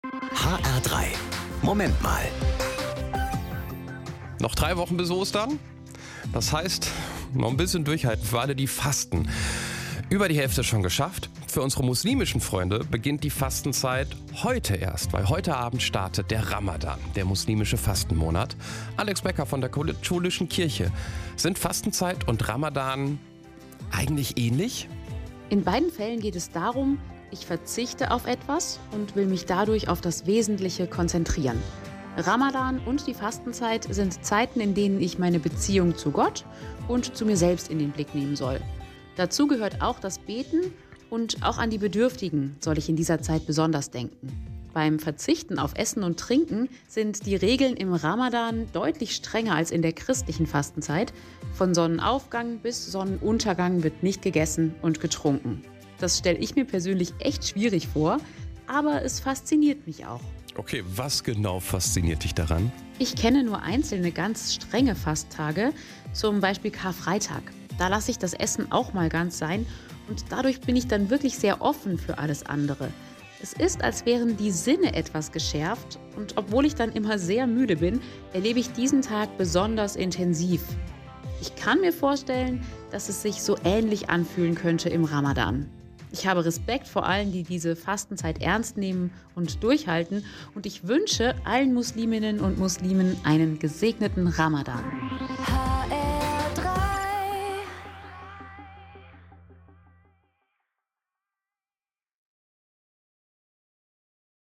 Katholische Pastoralreferentin Frankfurt